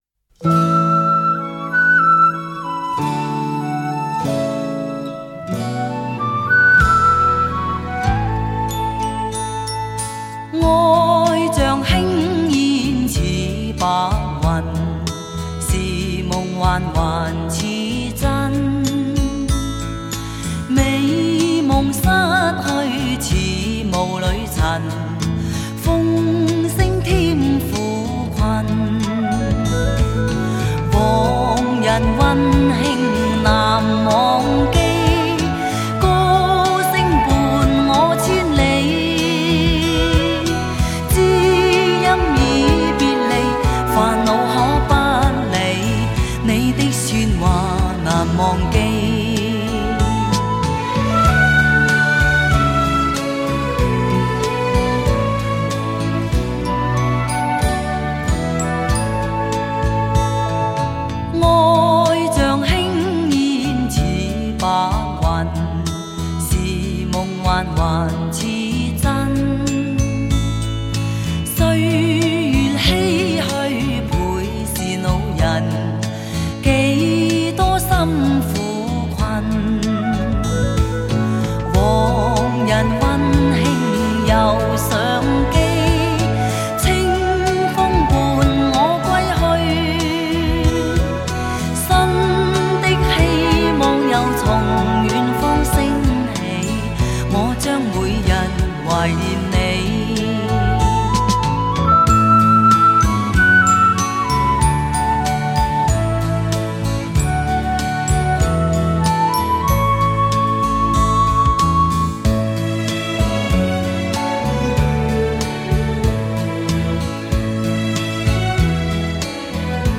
音色更接近模拟(Analogue)声效
强劲动态音效中横溢出细致韵味